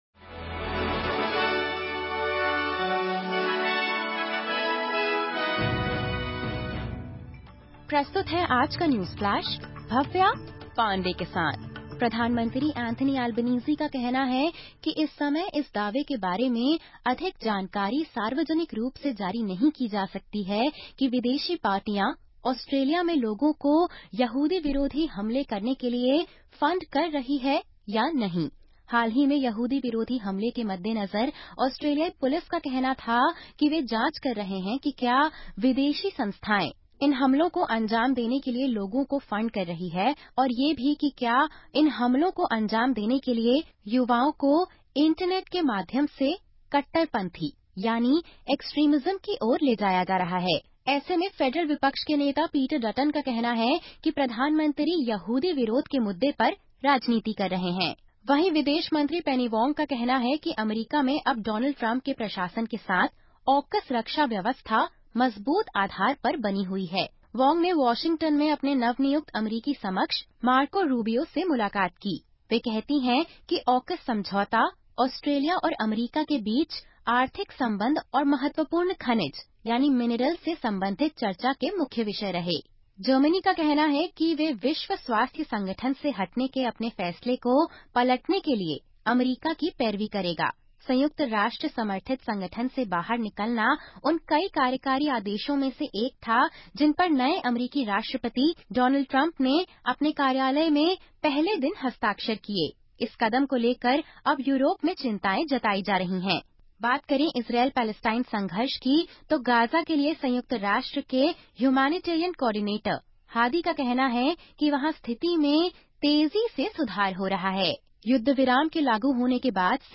सुनें ऑस्ट्रेलिया और भारत से 22/01/2025 की प्रमुख खबरें।